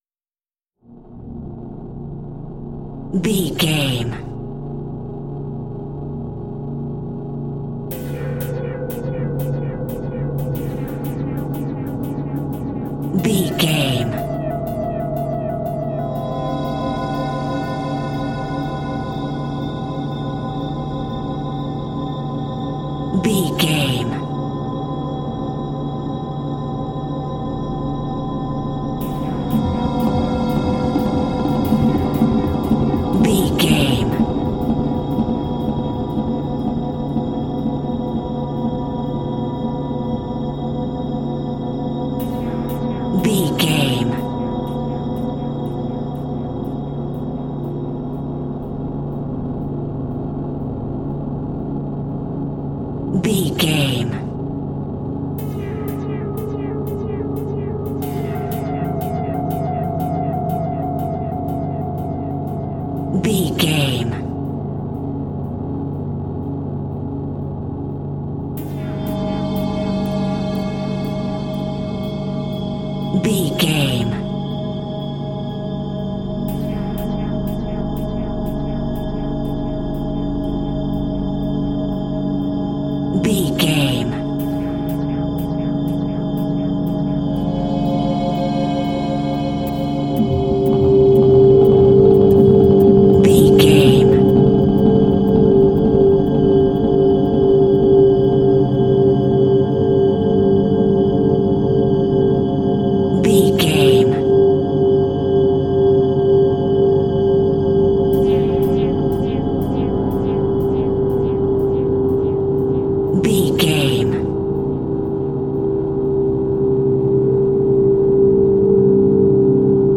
Space Echo Music.
In-crescendo
Aeolian/Minor
ominous
dark
suspense
eerie
strings
synth
ambience
pads
eletronic